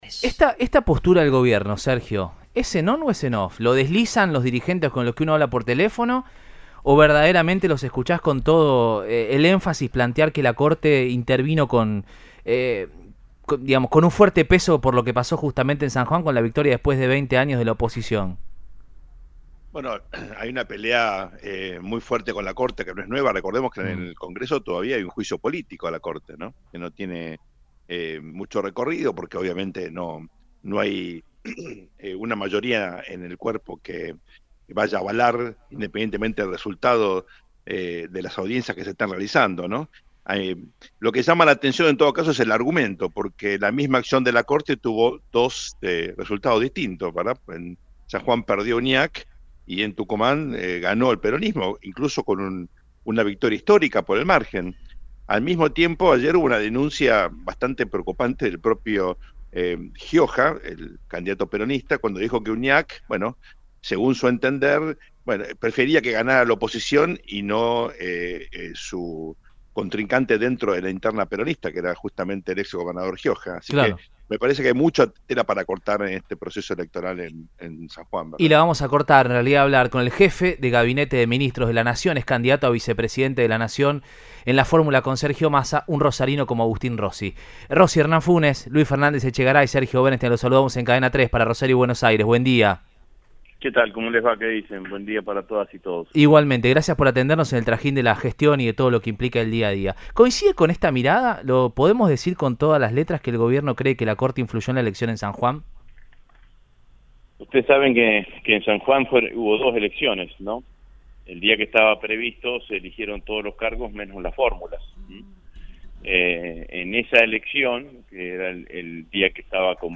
El actual Jefe de Gabinete de Nación y precandidato a vicepresidente por UxP dialogó con Cadena 3 Rosario y brindó su mirada sobre la actualidad e hizo referencia al proceso electoral sanjuanino y de Santa Fe.